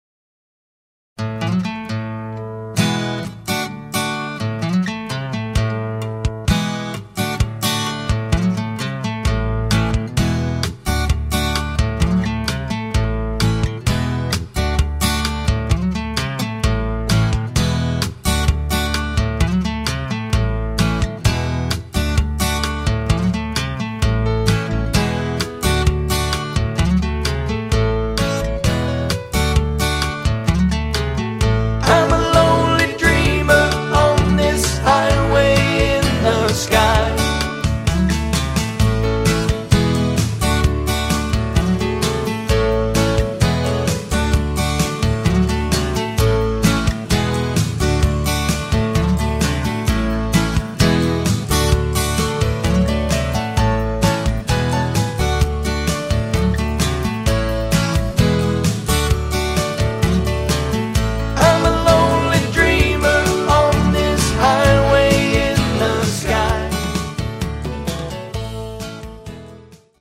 Harmony